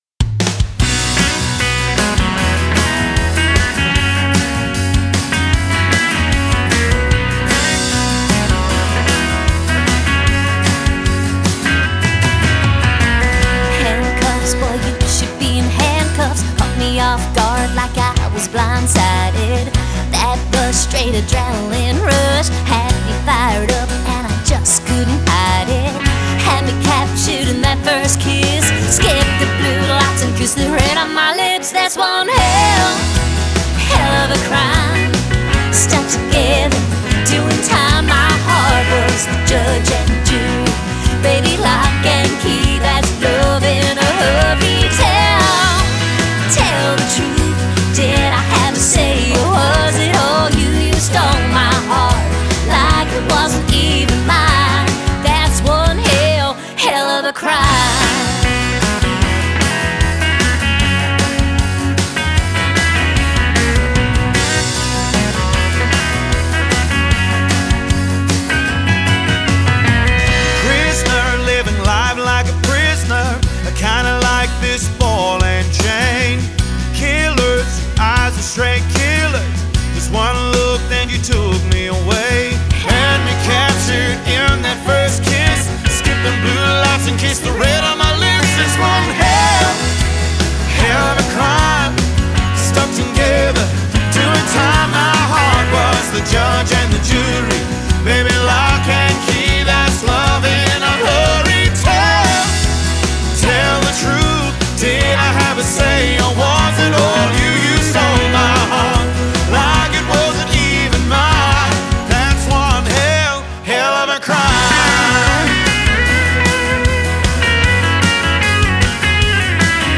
country music
energetic, rocky number